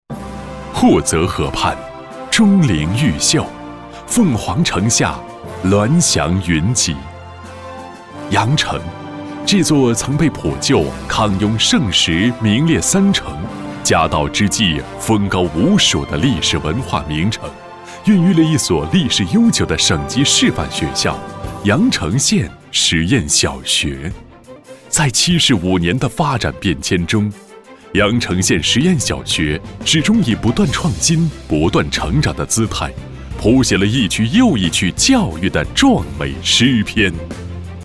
大气浑厚 学校宣传
高端男音，大气浑厚男音，有力度，适合片头宣传片，汇报片配音，形象片配音等。